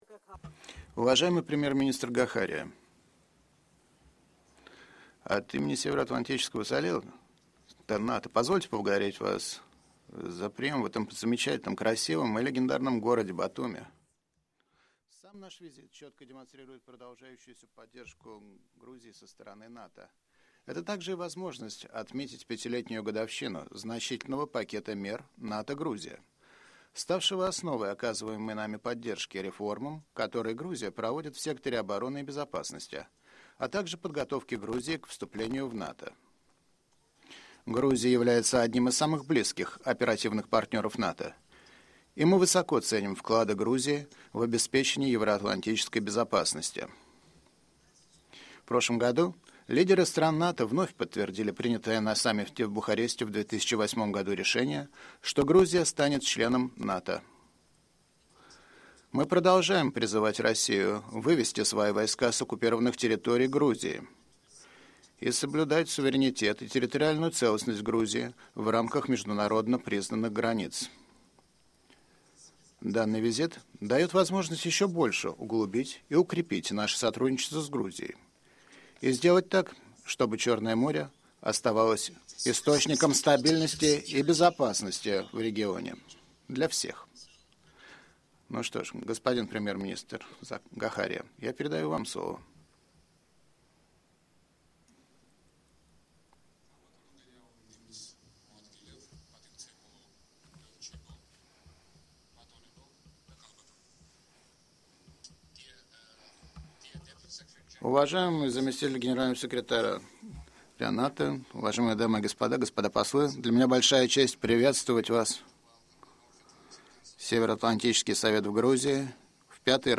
Opening remarks
by NATO Deputy Secretary General Rose Gottemoeller and Giorgi Gakharia, Prime Minister of Georgia at the NATO-Georgia Commission